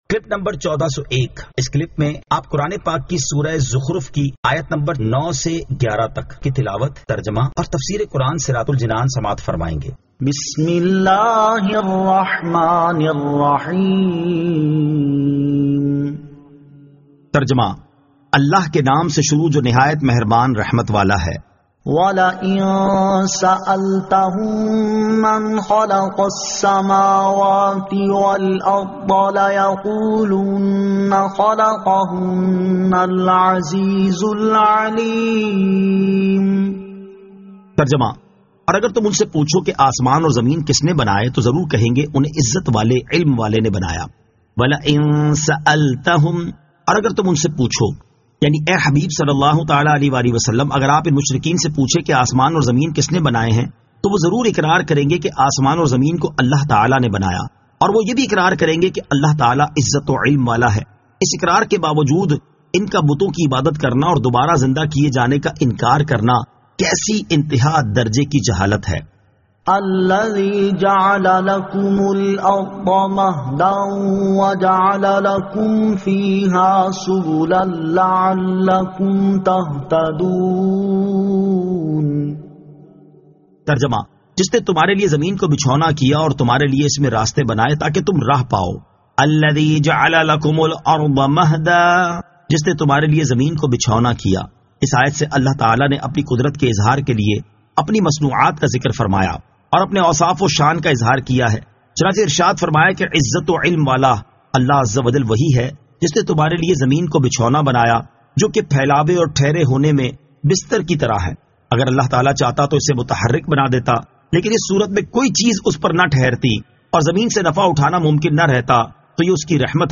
Surah Az-Zukhruf 09 To 11 Tilawat , Tarjama , Tafseer